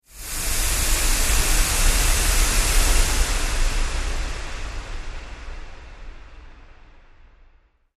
Processed Air Release